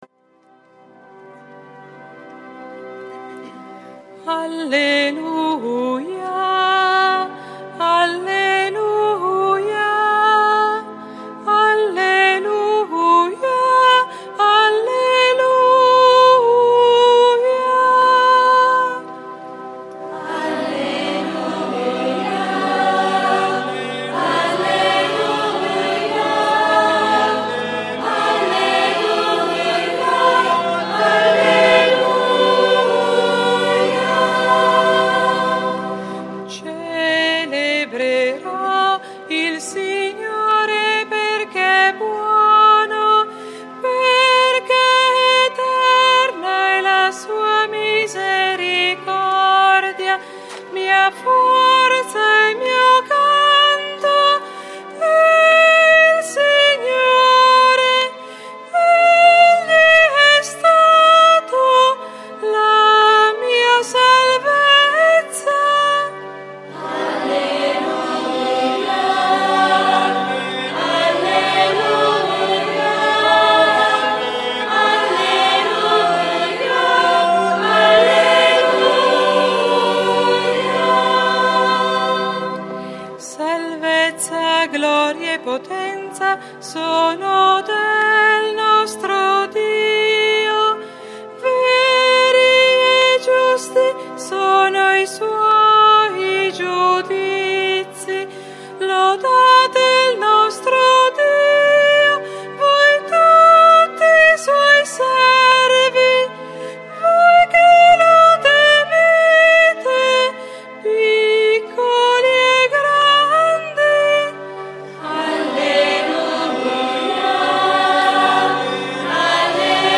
SABATO SANTO -Celebrazione della Resurrezione del Signore